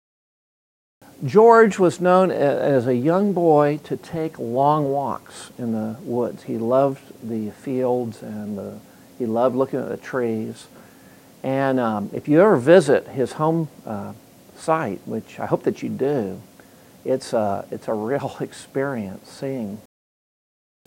This workshop discusses God's Providential preparation of George Washington Carver as a leader in education.